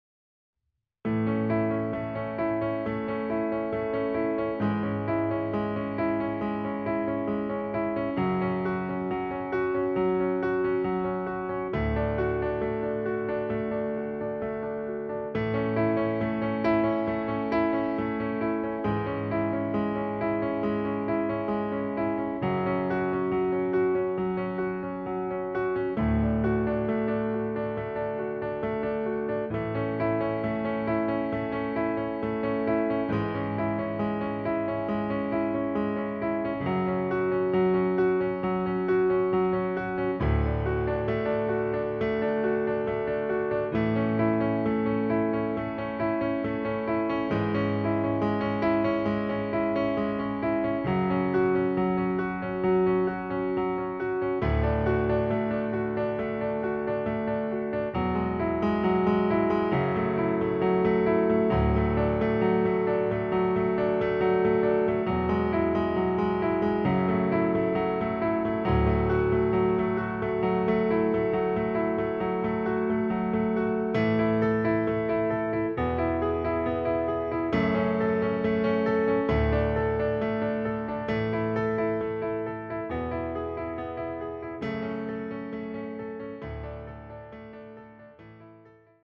fortepianowe instrumentalne ścieżki pod wokal